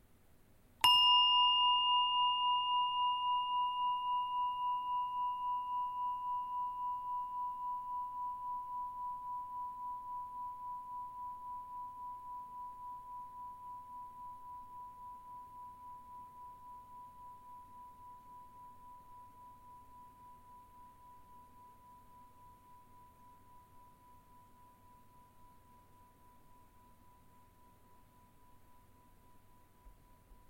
Klangschalen zu den Klangschalenuhren